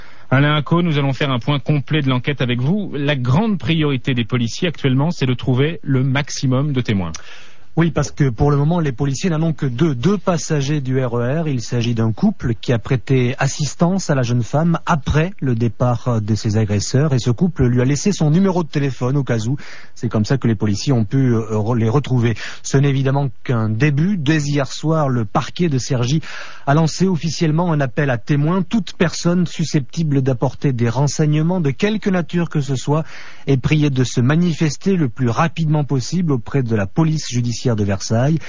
[1Certains des extraits sonores qui suivent résultent d’un montage.